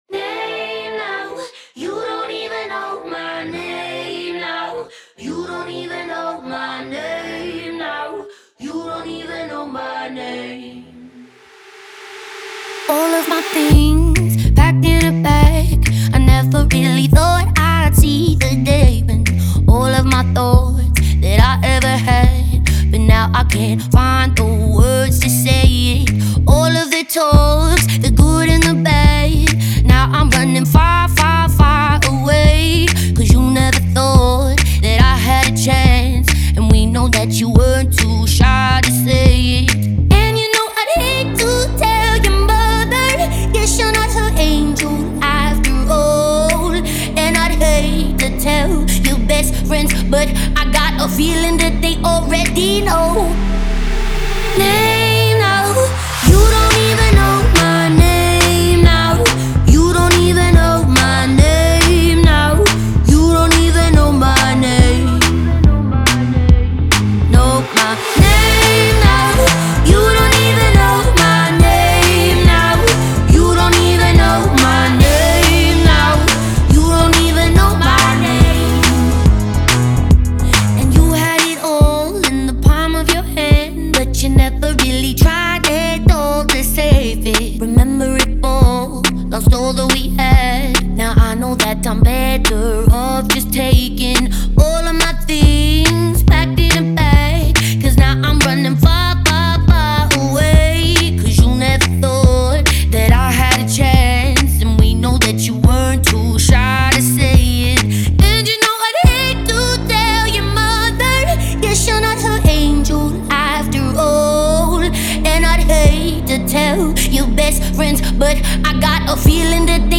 запоминающаяся поп-песня австралийской певицы
своим уникальным голосом и эмоциональной подачей